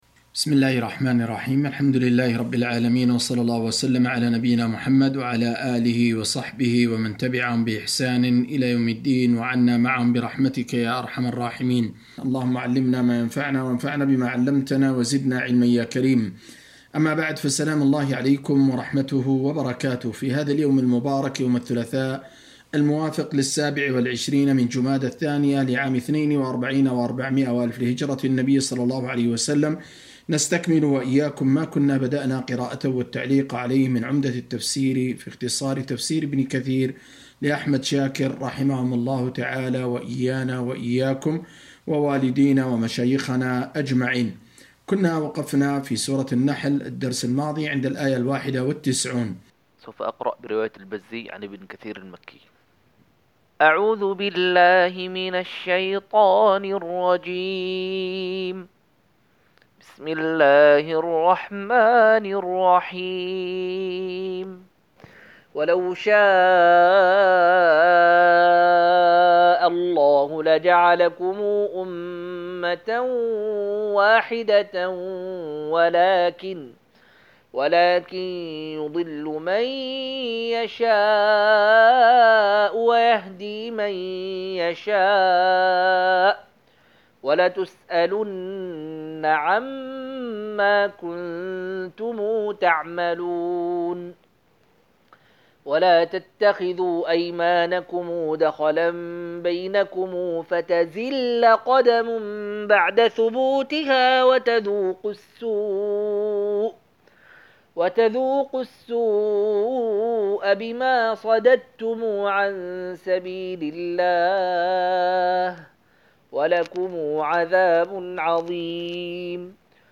257- عمدة التفسير عن الحافظ ابن كثير رحمه الله للعلامة أحمد شاكر رحمه الله – قراءة وتعليق –